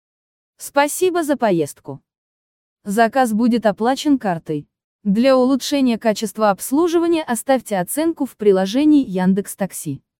Звуки таксометра